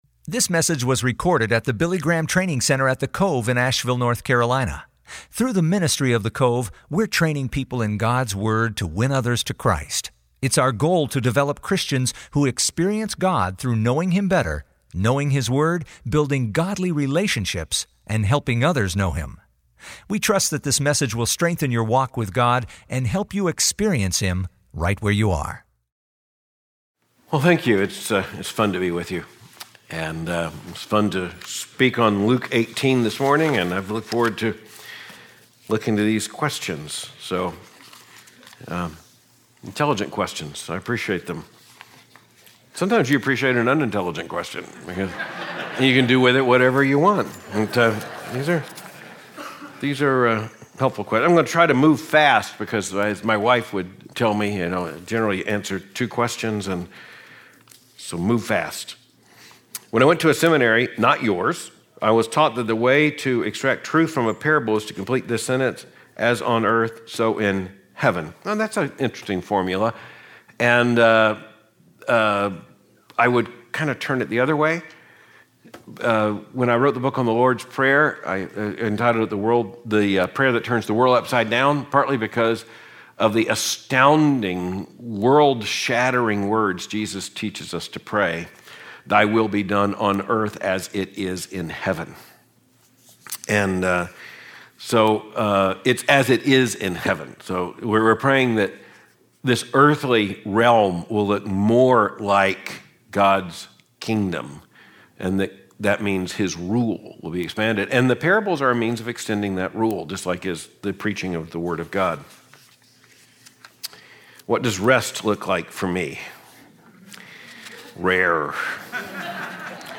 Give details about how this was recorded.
Billy Graham Training Center at The Cove